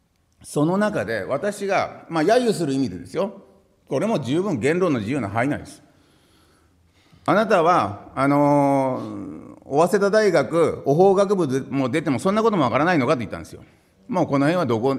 資料3　井桁議員が行った計4回の懲罰に対する弁明　音声②　（音声・音楽：184KB）